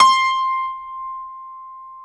SG1 PNO  C 5.wav